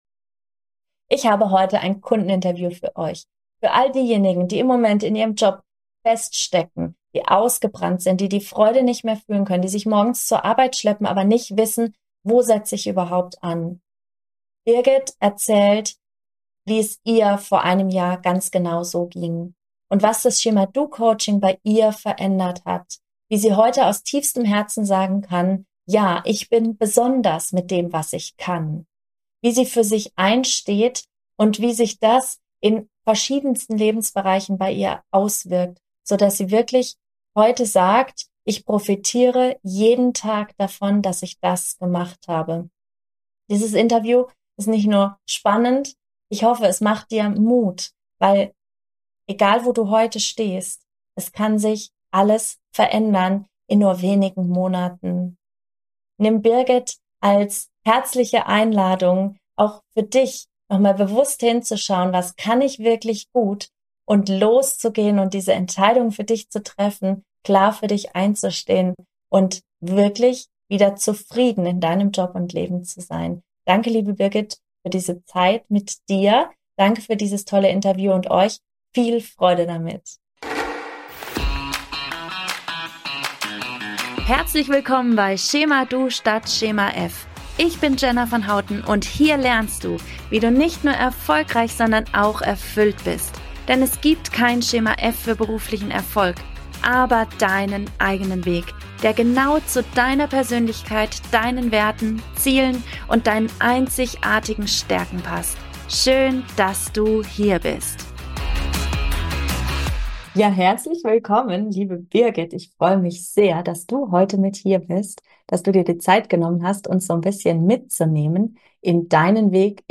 In dieser Folge wartet ein Interview auf dich. Es ist für all diejenigen, die im Moment in ihrem Job feststecken.